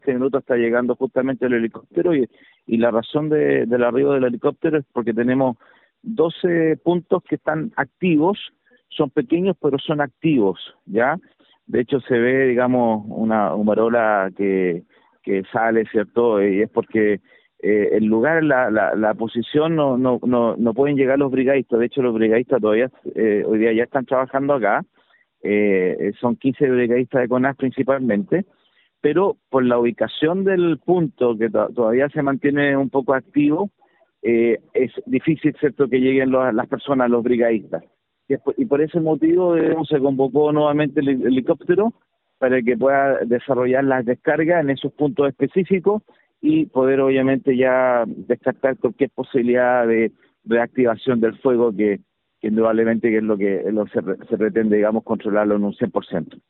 El jefe comunal agregó que se desplegó en la zona un helicóptero de la Corporación Nacional Forestal, CONAF, junto a un grupo de brigadistas que se mantienen en el lugar por la presencia focos activos, por la dificultad de acceso al sector siniestrado.